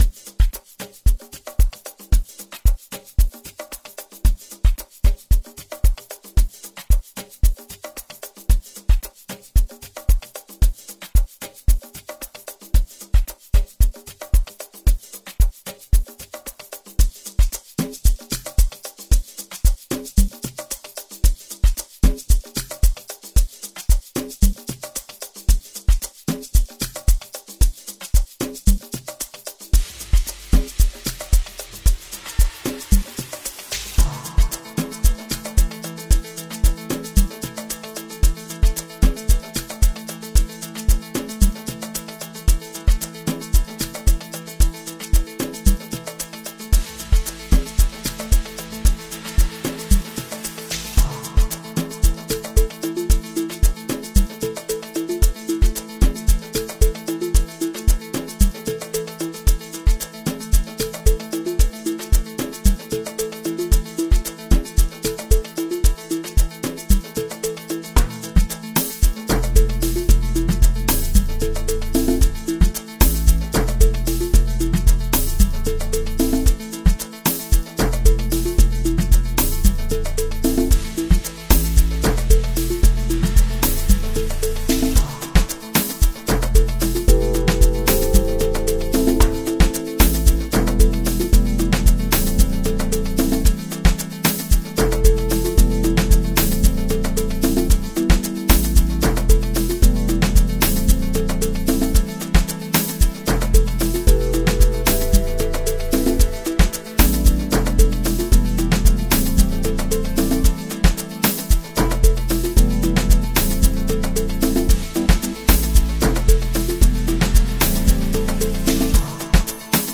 catchy tune